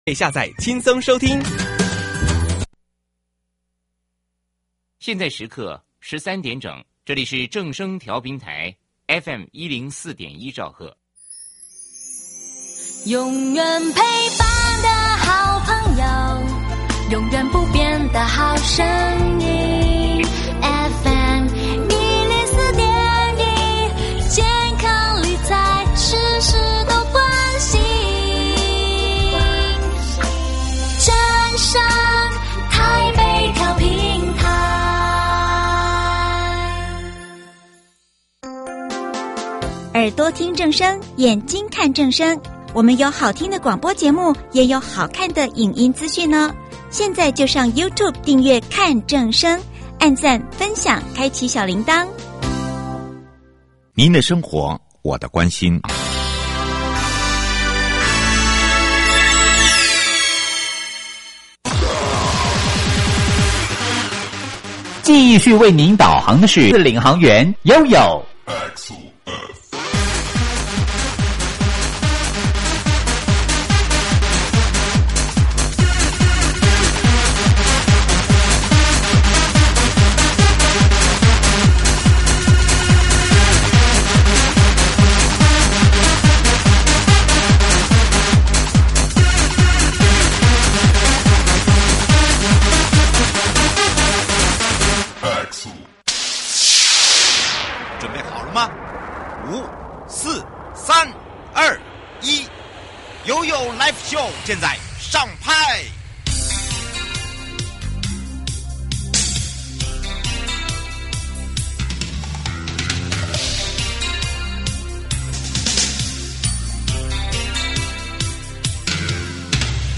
受訪者： 1.國土署都市基礎工程組 2.新竹縣政府交通旅遊處 陳盈州處長(三)